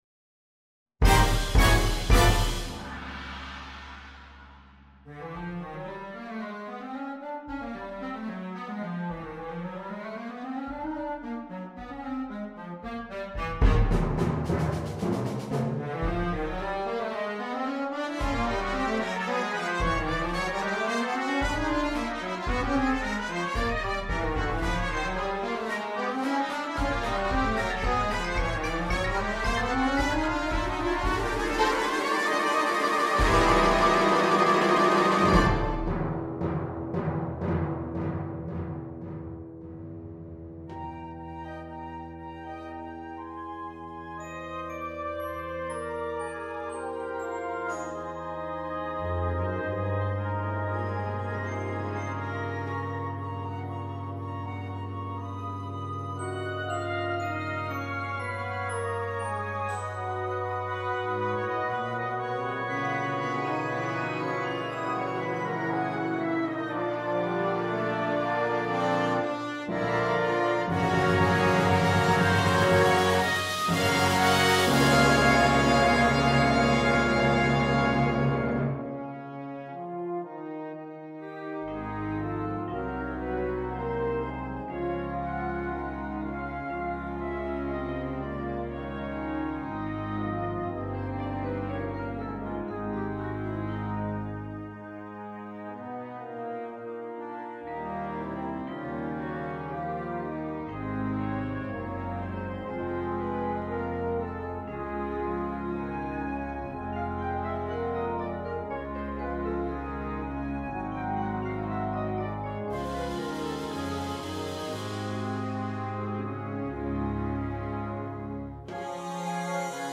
Besetzung: Concert Band